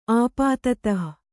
♪ āpātatah